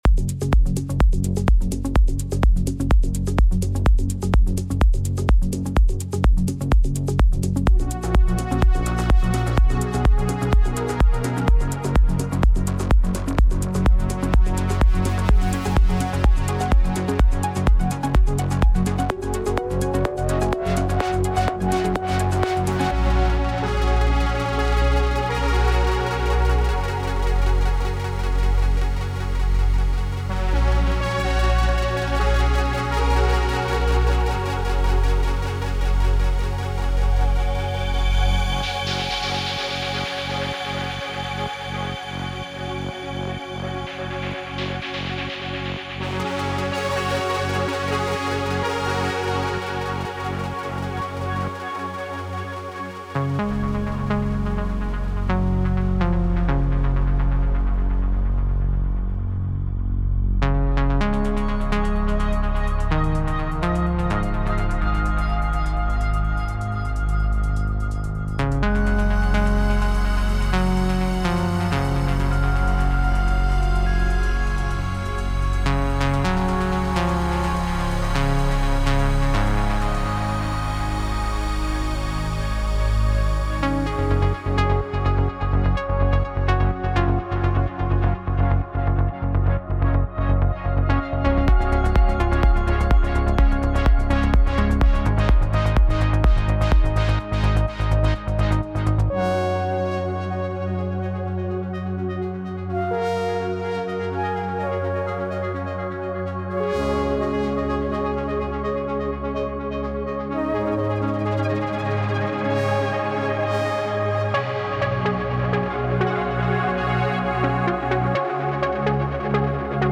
melodic techno presets
MP3 DEMO